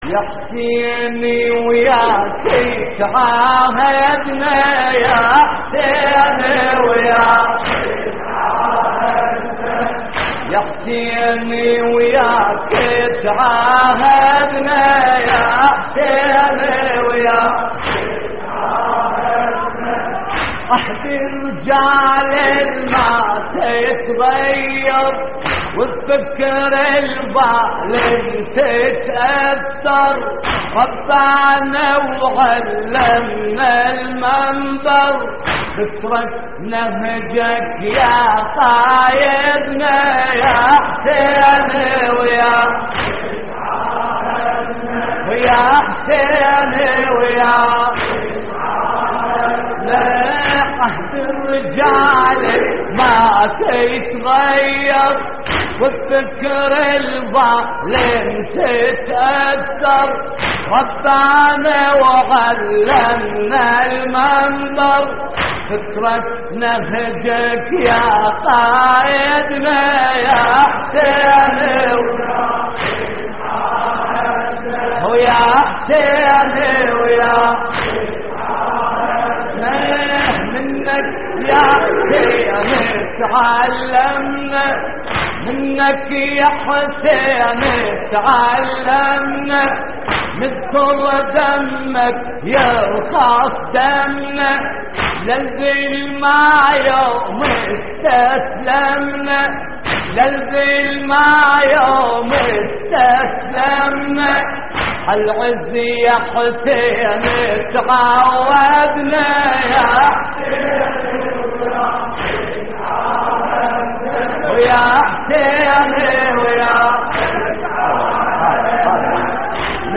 اللطميات الحسينية